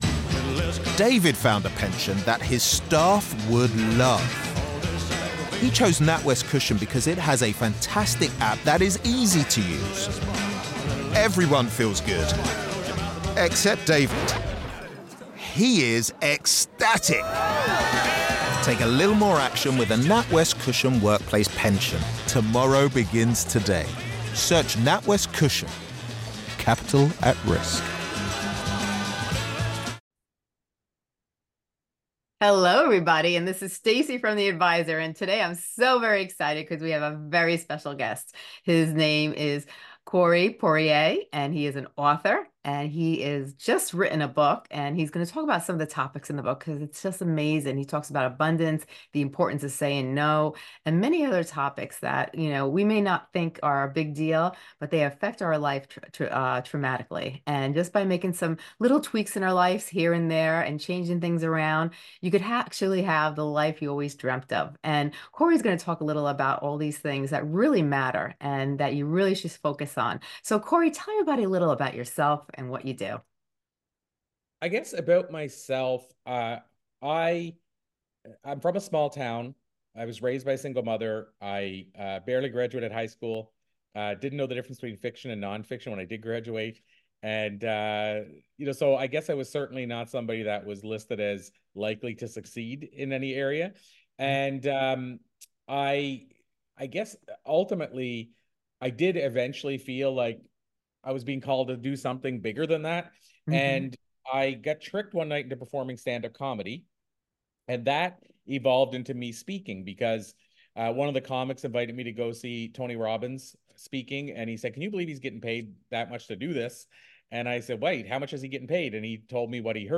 Don't miss this enlightening conversation that will empower you to take charge of your life and soar to new heights. http